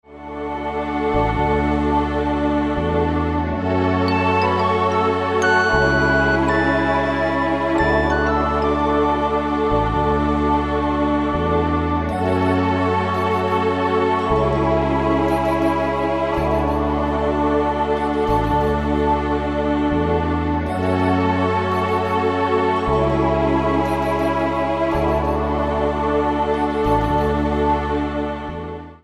Piękna harmonijna muzyka do masżu.